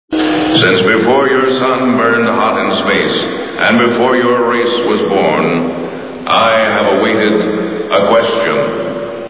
Star Trek TV Show Sound Bites